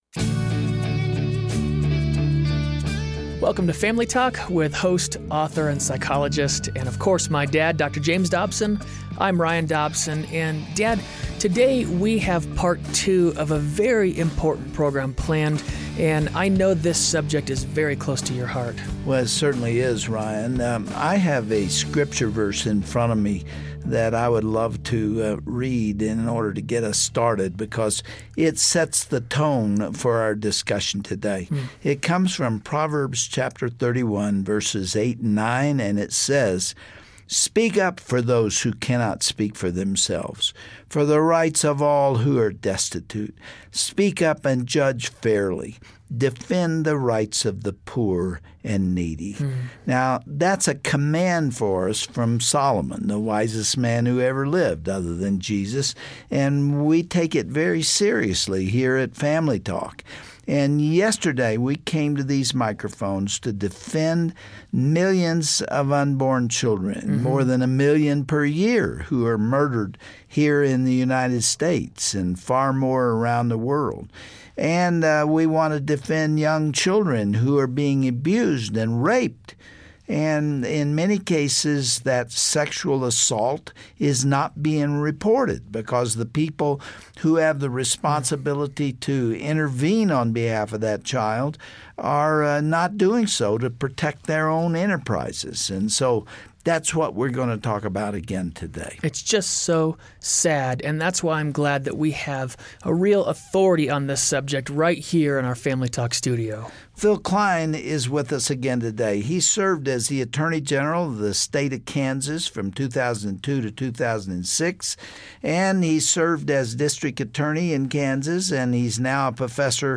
Hear all the facts first-hand from former Attorney General of Kansas, Phill Kline, who helped expose what is happening behind the scenes with the country's leading abortion provider.